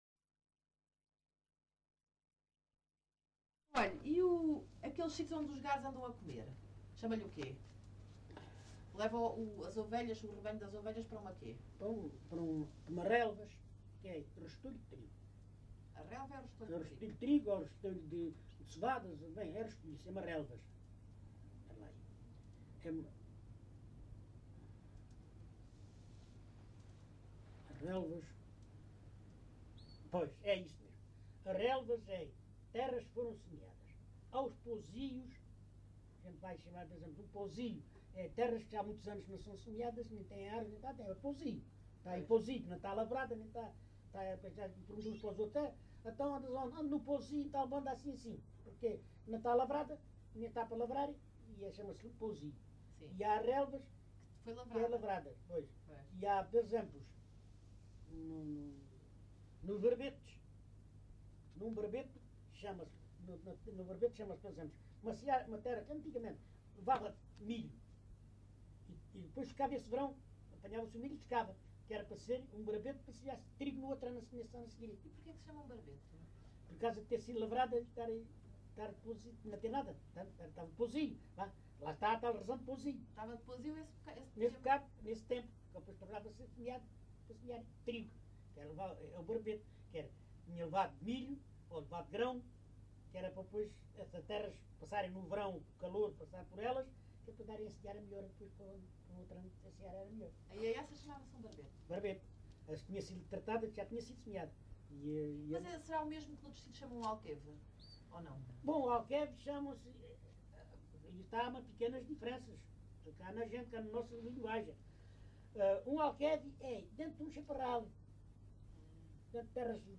LocalidadeLavre (Montemor-o-Novo, Évora)